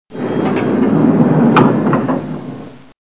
Freight elevator door
Category: Sound FX   Right: Personal
Tags: Elevator Sounds Elevator Elevator Sound clips Elevator sound Sound effect